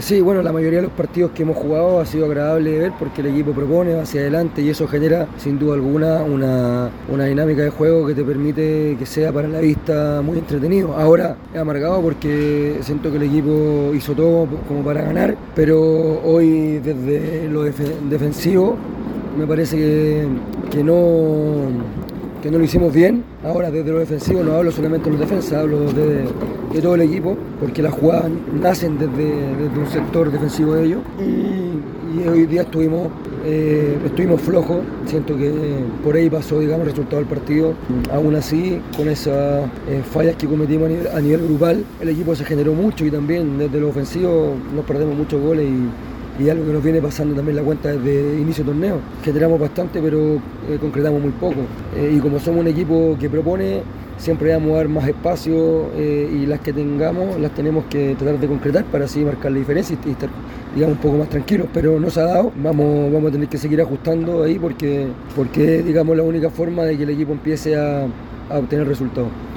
Así lo reconoció en diálogo con Primera B Chile.